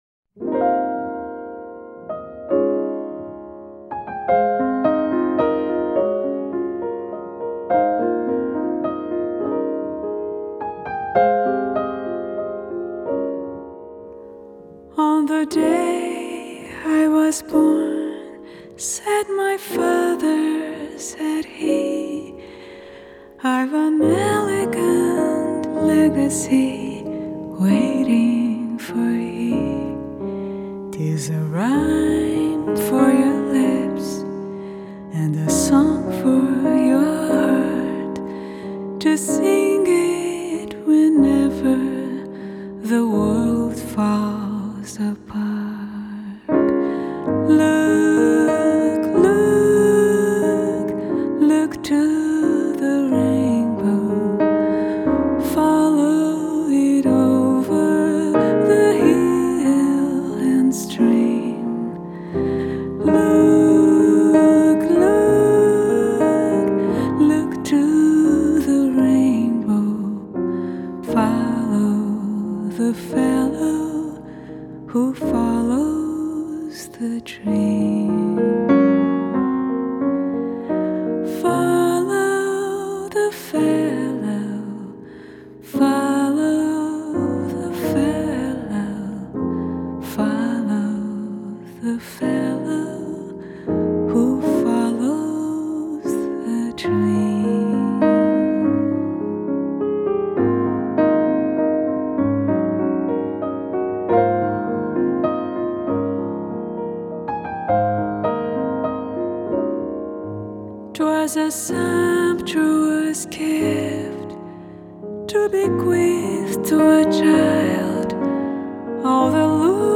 the fine bossa nova singer